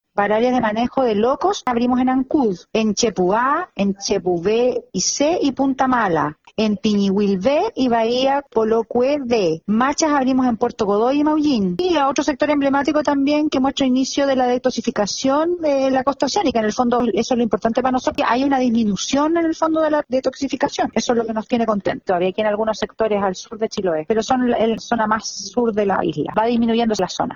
Scarlet Molt, Seremi de Salud en Los Lagos, detalló los sectores que fueron liberados.